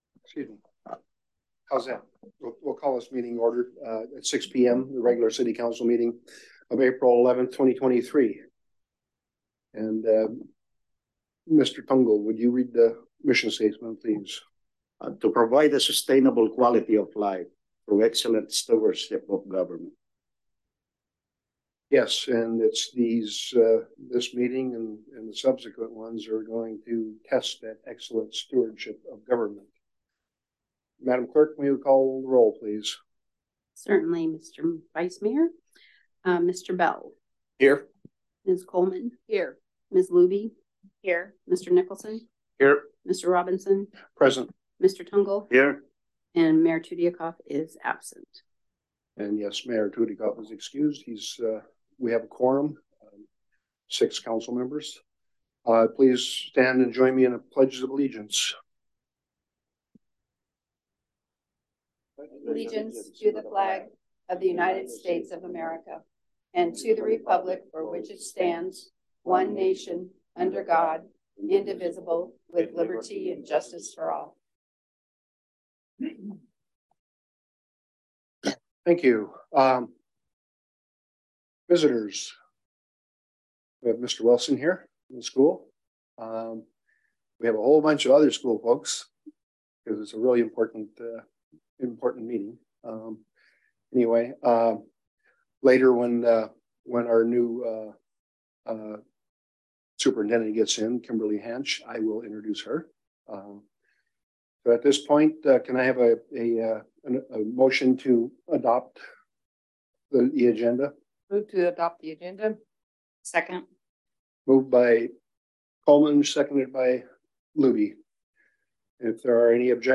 City Council Meeting - April 11, 2023 | City of Unalaska - International Port of Dutch Harbor